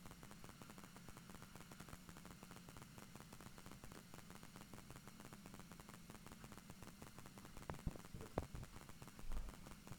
You plug in the modem — and you hear a nasty glitch.
▶ Sound of MEMS next to the modem, stereo
6_mems_mono_16bit_near-modem.wav